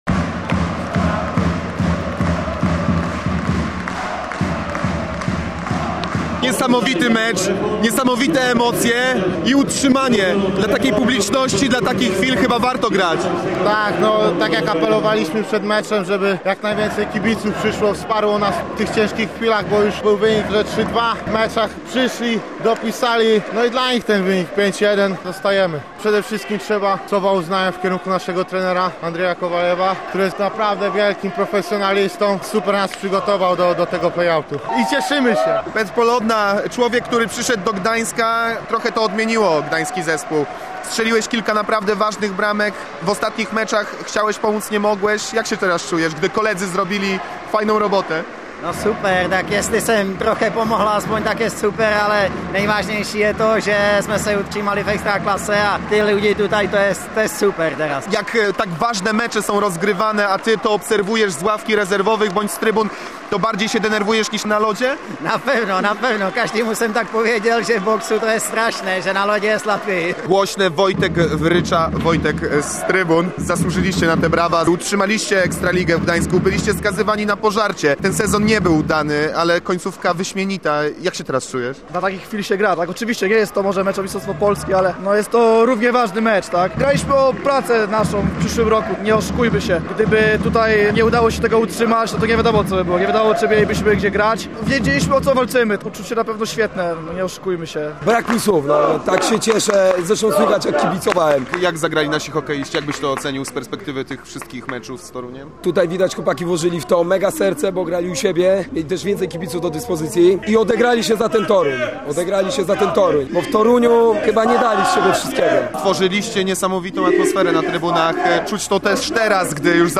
Decydujące starcie w hali Olivia obejrzała rekordowa publiczność. 3100 gardeł przez trzy tercje dopingowało gdańskich hokeistów, a ci odpłacili się pięcioma bramkami i ostatecznym triumfem w rywalizacji play-out.